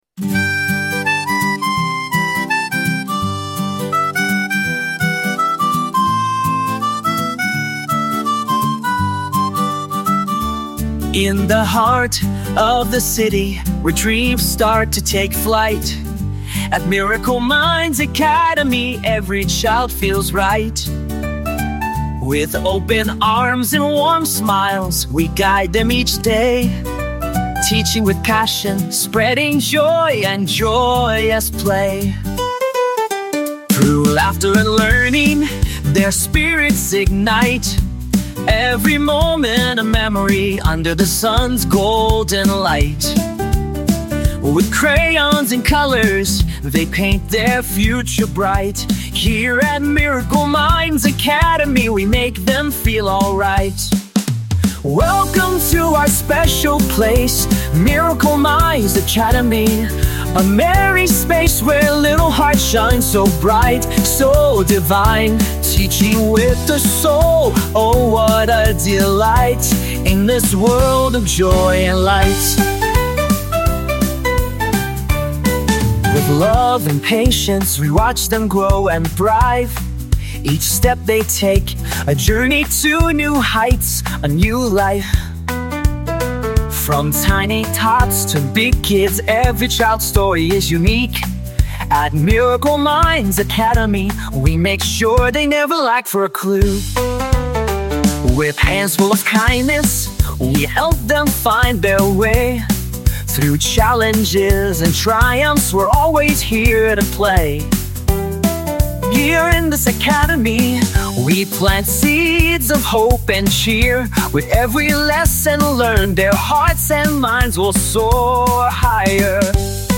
School Anthem | Miracle Minds Academy
anthem.mp3